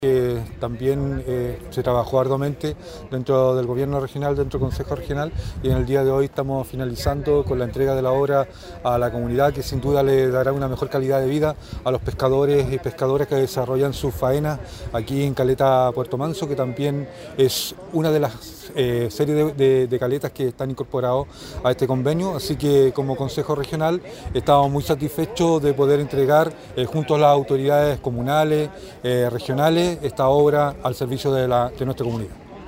Finalmente, el consejero regional David Muñoz, señaló que esta obra es el
CORE-DAVID-MUNOZ.mp3